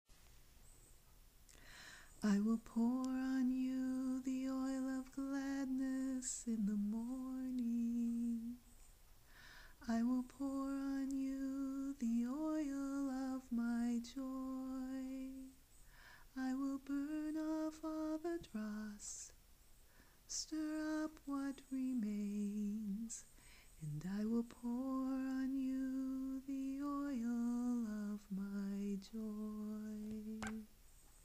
Amazingly, yes that is the song that I remember hearing in the dream. It took several years before I was brave enough to sing it out loud.